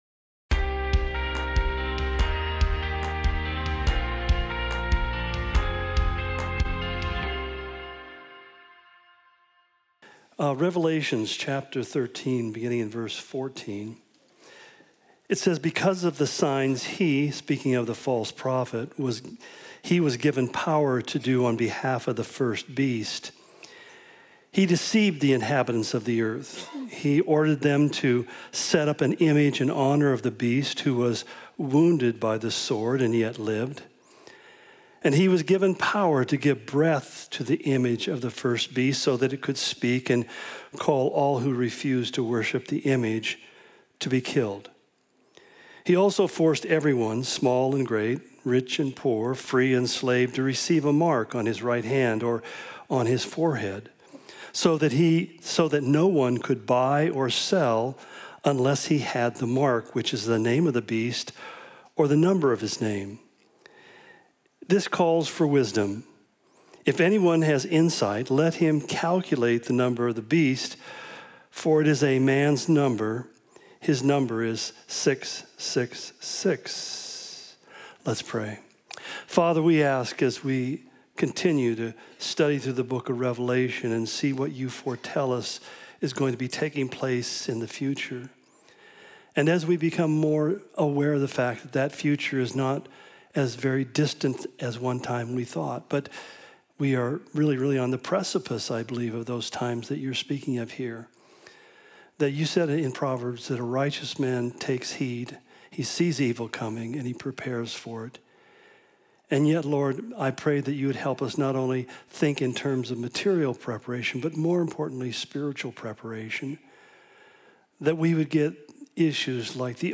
Calvary Spokane Sermon Of The Week podcast To give you the best possible experience, this site uses cookies.